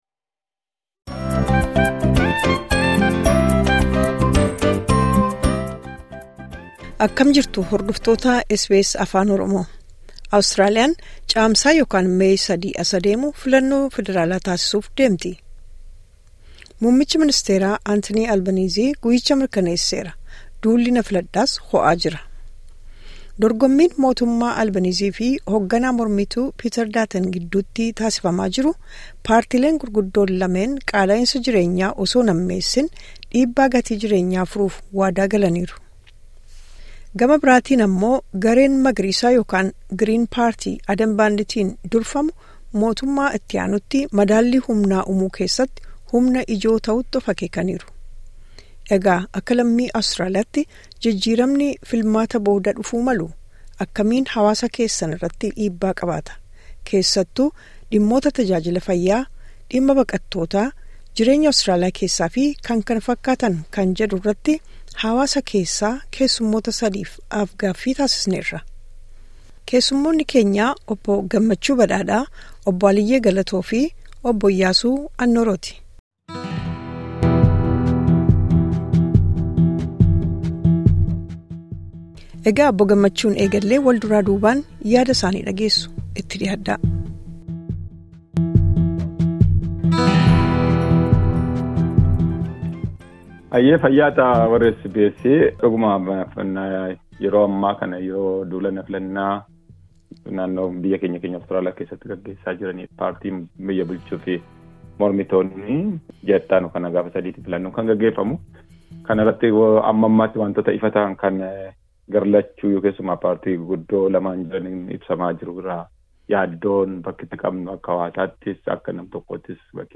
Aaf-gaaffii